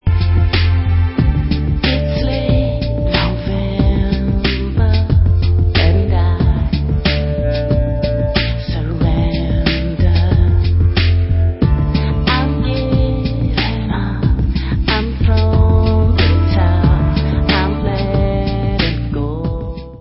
sledovat novinky v kategorii Pop